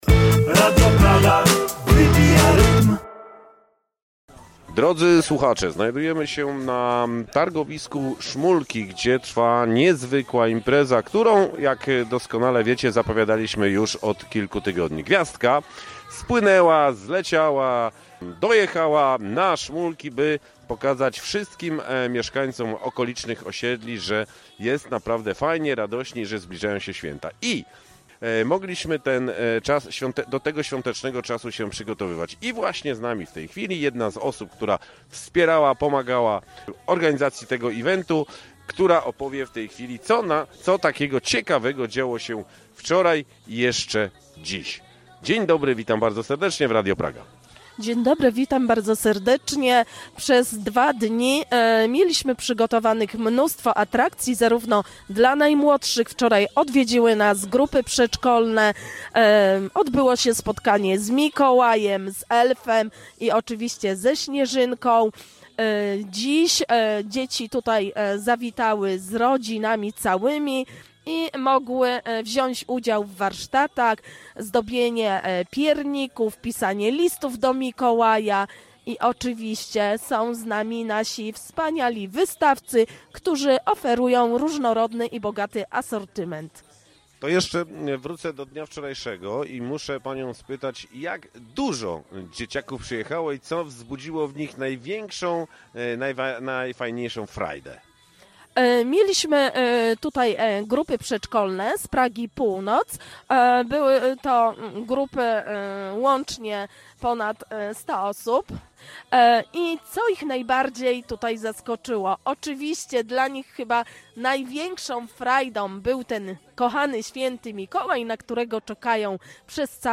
O tym co tam się działo, możecie posłuchać na specjalnie przygotowanej relacji.